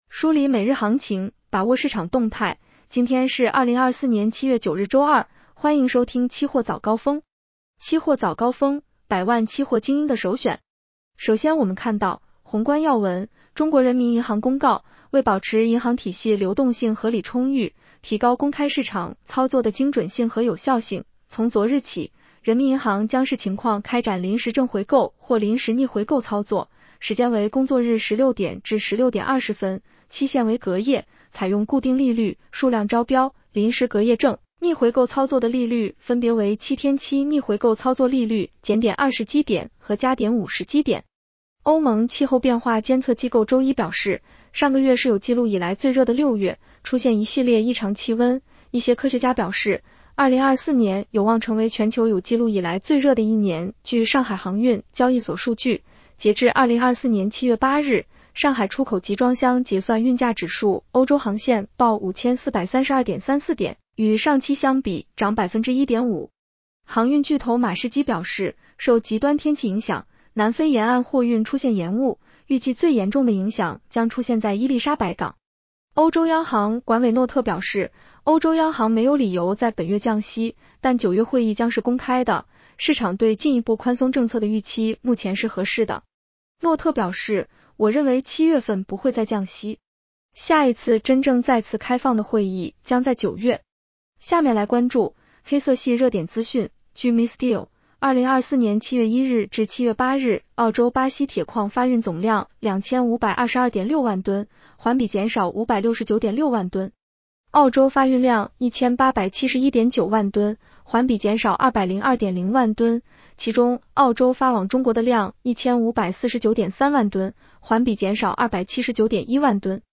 期货早高峰-音频版 女声普通话版 下载mp3 宏观要闻 1.中国人民银行公告，为保持银行体系流动性合理充裕，提高公开市场操作的精准性和有效性，从昨日起， 人民银行将视情况开展临时正回购或临时逆回购操作 ，时间为工作日16:00-16:20，期限为隔夜，采用固定利率、数量招标，临时隔夜正、逆回购操作的利率分别为7天期逆回购操作利率减点20bp和加点50bp。